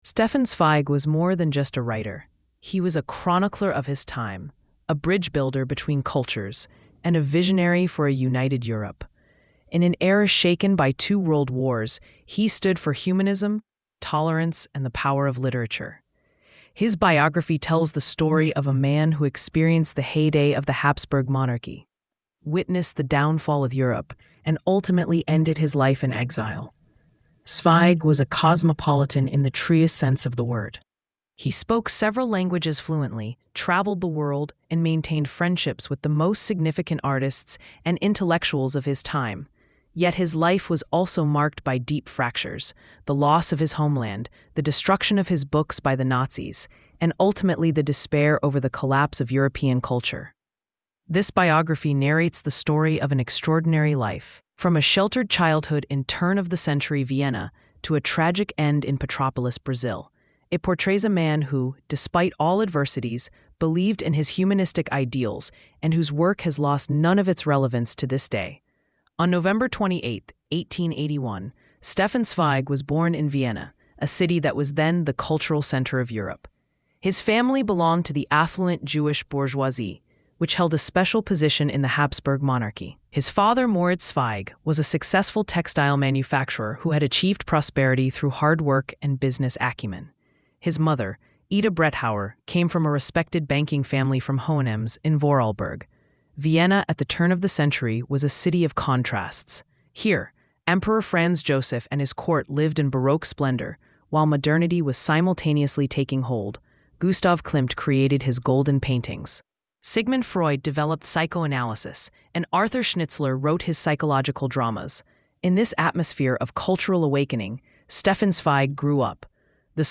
🎧 Comprehensive Audio Biography Available
Stefan_Zweig_Biography_Complete_EN.mp3